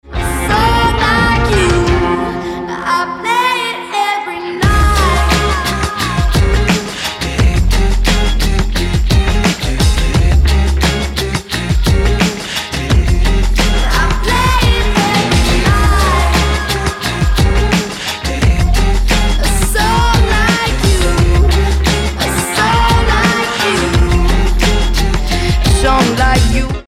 • Качество: 320, Stereo
поп
забавные
озорные